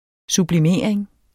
sublimering substantiv, fælleskøn Bøjning -en, -er, -erne Udtale [ subliˈmeˀɐ̯eŋ ] Betydninger 1.